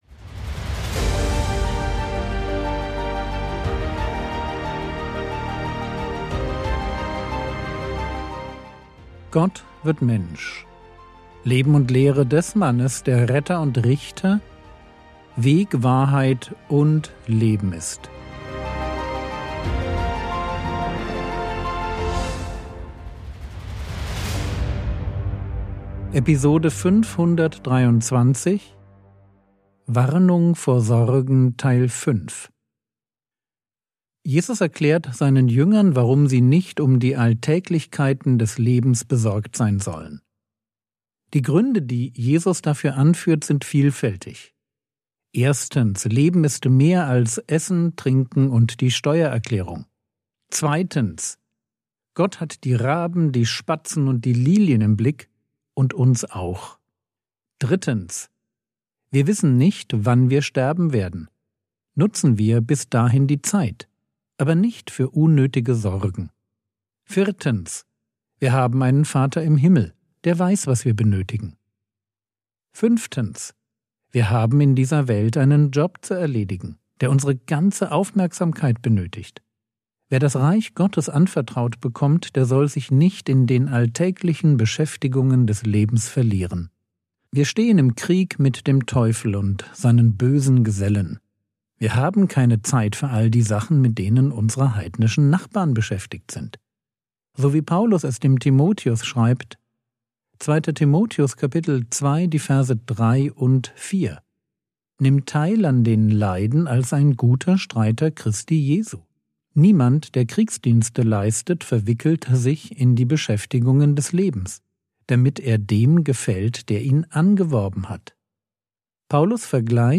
Frogwords Mini-Predigt